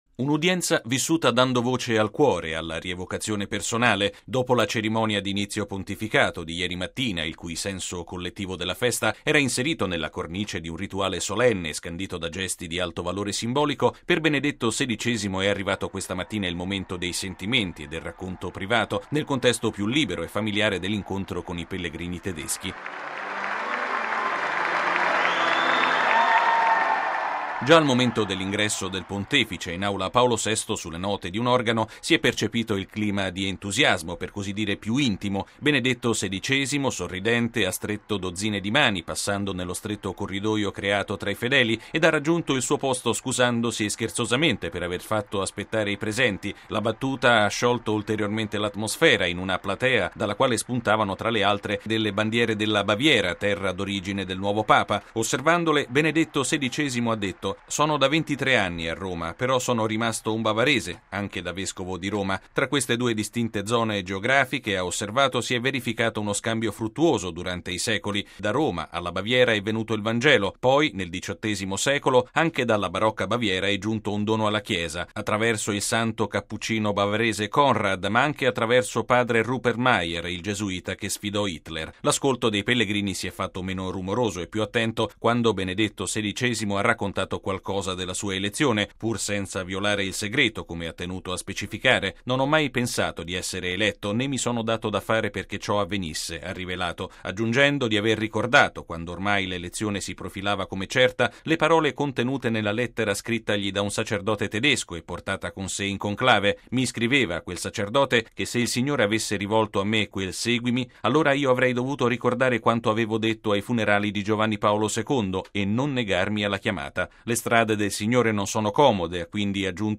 (26 aprile 2005 - RV) Ieri, subito dopo l’udienza interreligiosa, Benedetto XVI si è spostato in aula Paolo VI, dove lo attendevano migliaia di pellegrini tedeschi.